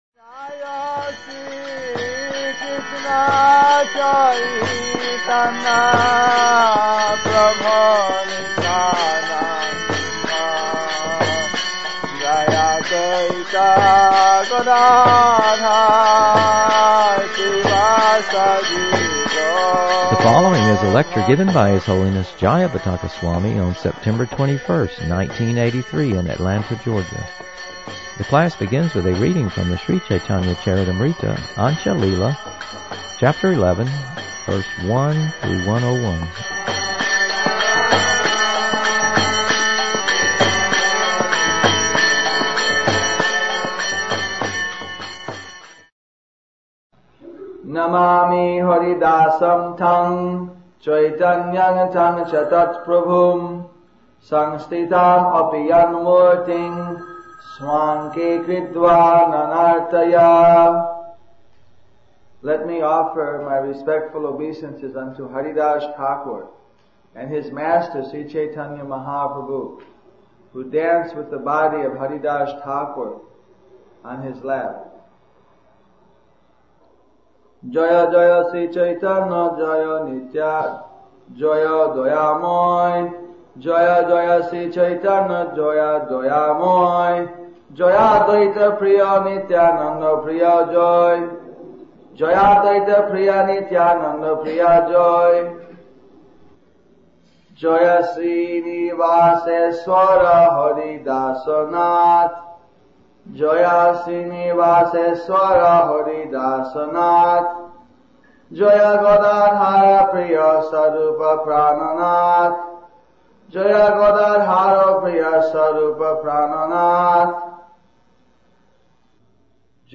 The class begins with a reading from the Sri Caitanya-Caritamrta, Antya-lila, Chapter 11, Verse 1 through 101.